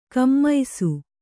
♪ kammaisu